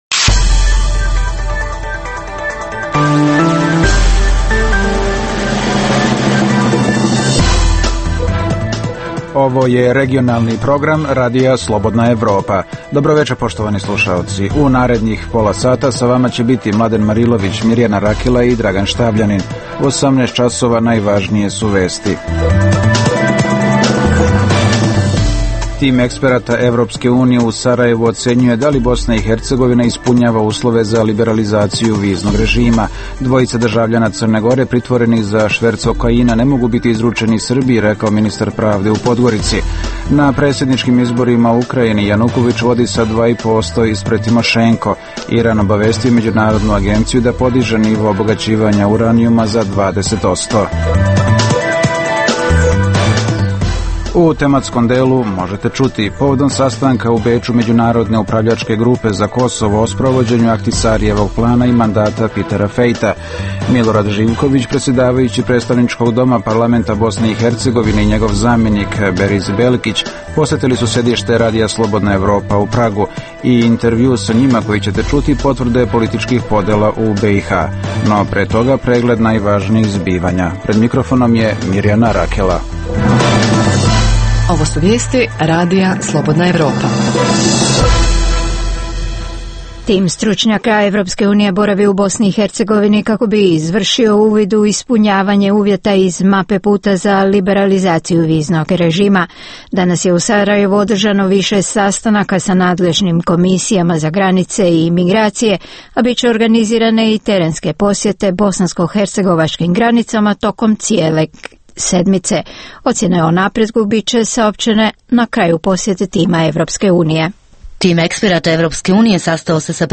I intervju sa njima koji možete čuti, potvrda je političkih podela u BiH. - Kosovo je dobilo podršku Međunarodne upravljačke grupe u planovima za evropsku integraciju, izjavili su zvaničnici Vlade Kosova posle sastanka ovog tela u Beču.